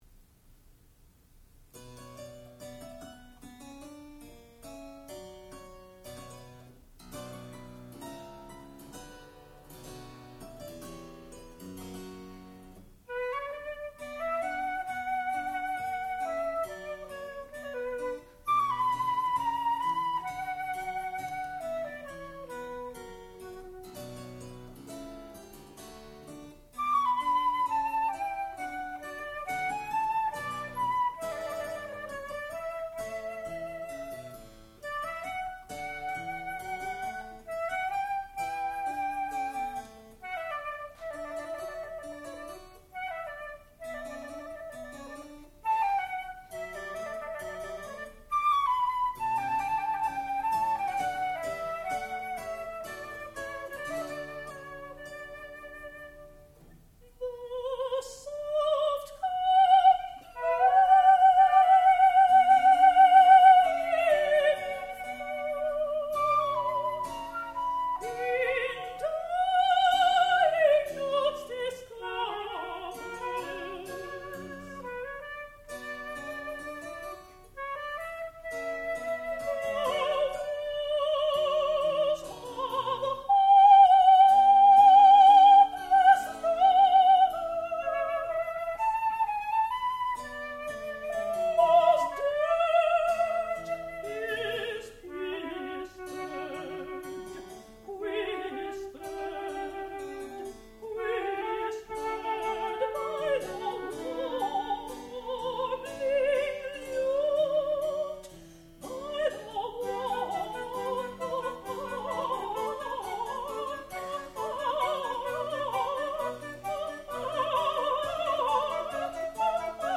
sound recording-musical
classical music
harpsichord
soprano